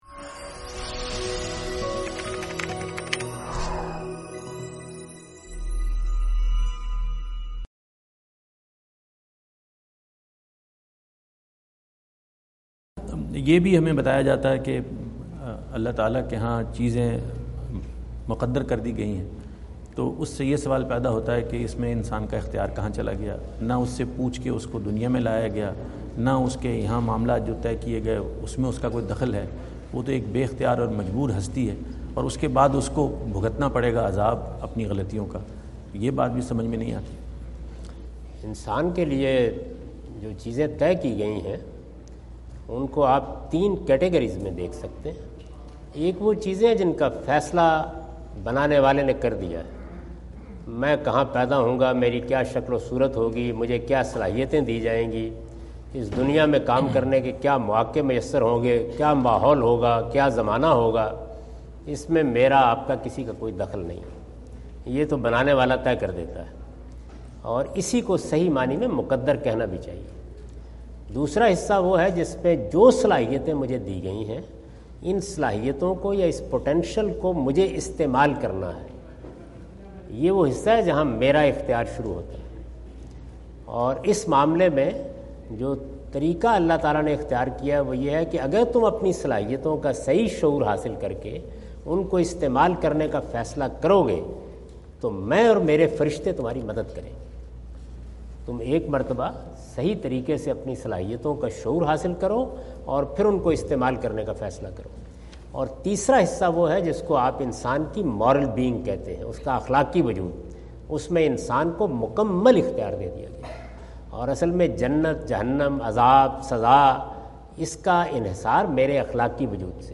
Category: English Subtitled / Questions_Answers /
Javed Ahmad Ghamidi answer the question about "Determinism and Free Will" During his US visit in Dallas on September 17, 2017.
جاوید احمد غامدی اپنے دورہ امریکہ 2017 کے دوران ڈیلس میں "ارادہ و اختیار" سے متعلق ایک سوال کا جواب دے رہے ہیں۔